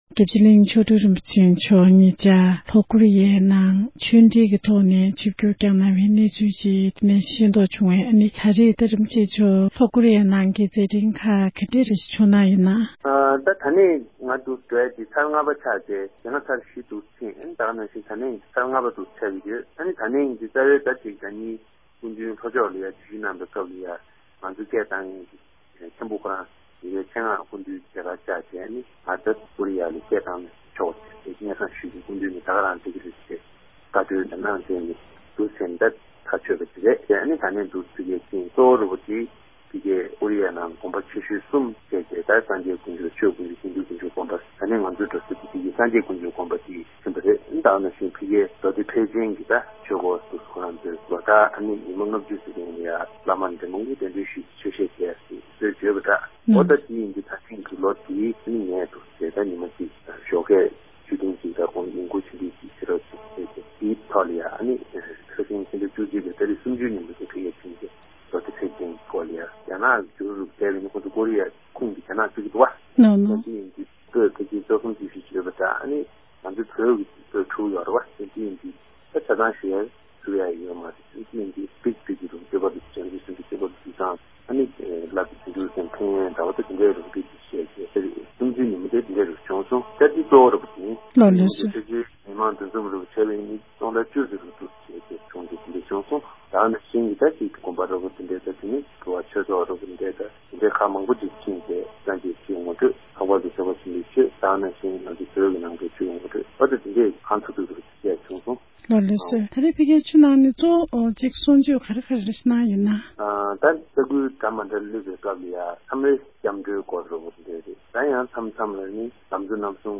འབྲེལ་ཡོད་མི་སྣར་བཅའ་འདྲི་ཞུས་པར་གསན་རོགས་གནང༌༎